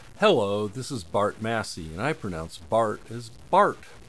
The book ships with a short voice recording you can try it on:
Channels       : 1
voice.wav